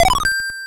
ClockGet.wav